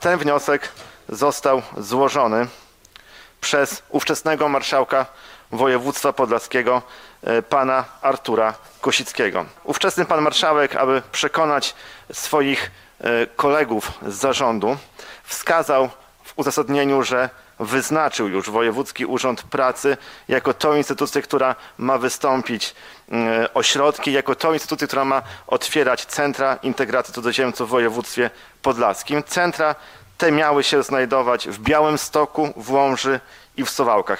Nie będzie w województwie podlaskim Centrów Integracji Cudzoziemców – zapewnił na nadzwyczajnej sesji sejmiku województwa podlaskiego marszałek Łukasz Prokorym.